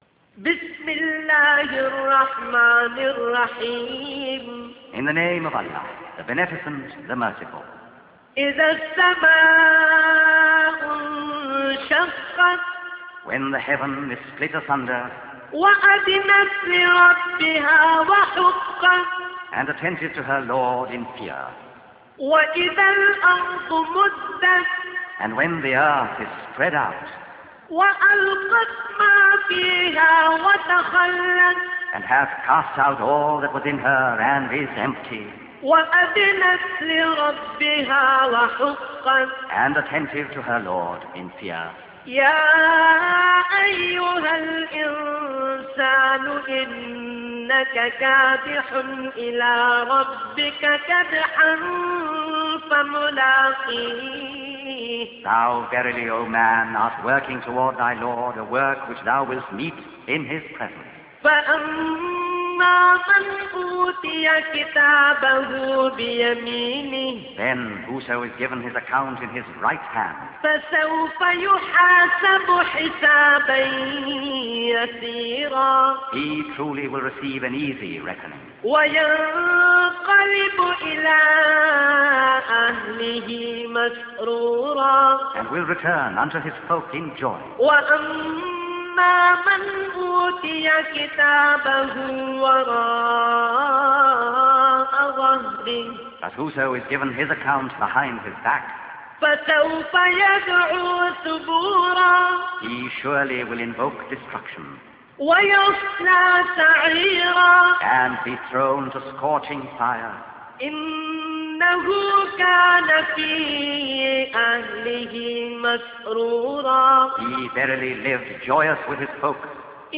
· Recitation of Quran